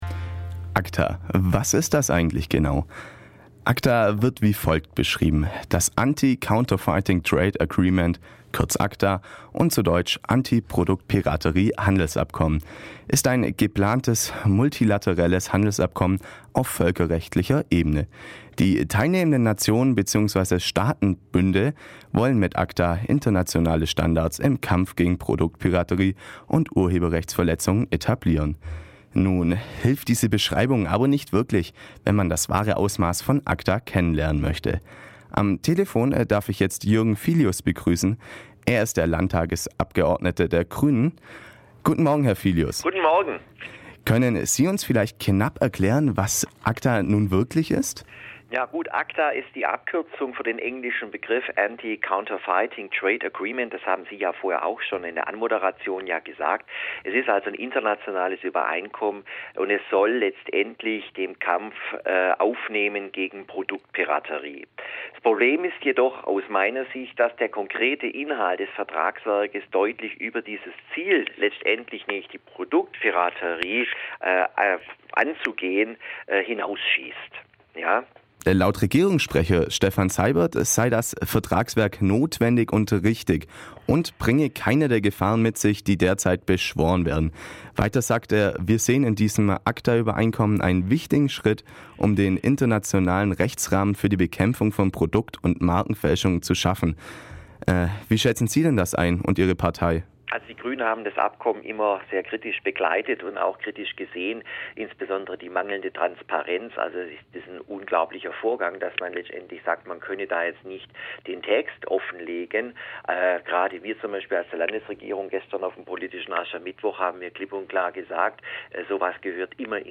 Herr Filius, Landtagsabgeordneter der Grünen, ist einer von diesen und wird sich heute unseren Fragen stellen.